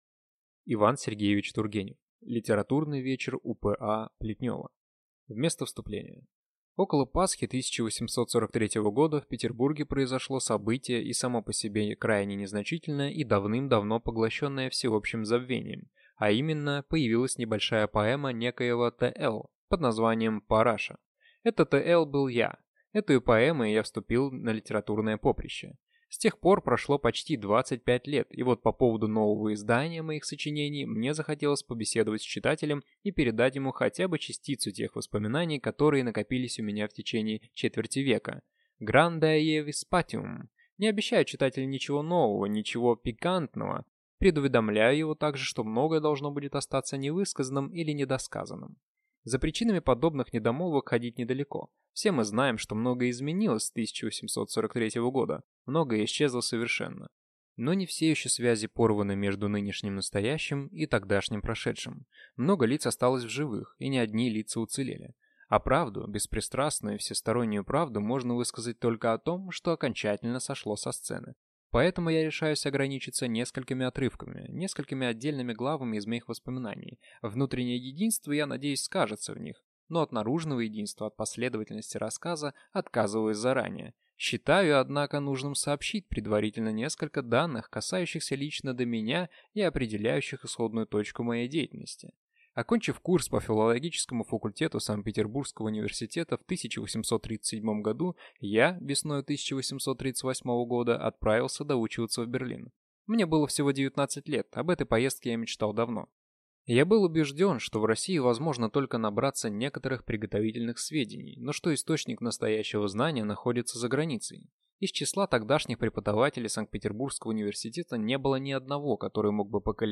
Аудиокнига Литературный вечер у П.А. Плетнева | Библиотека аудиокниг